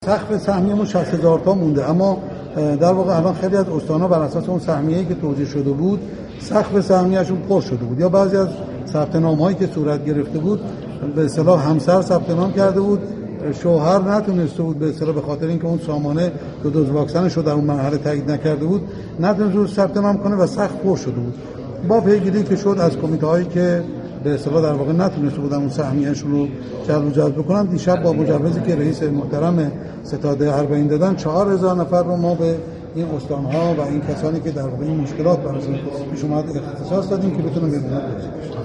به گزارش رادیو زیارت، علی رضا رشیدیان امروز در نشست خبری کمیته اعزام ستاد اربعین با بیان اینکه سازمان حج و زیارت مجری سیاست‌ های ستاد اربعین در زمینه اعزام زوار اربعین است، اظهار داشت : از 156 هزار نفر ثبت نام شده، نام نویسی 52 هزار و 817 نفر قطعی شده و تاکنون 34 هزار نفر با همکاری عراق و کنسولگری تهران، مشهد و اهواز ویزا گرفته‌اند.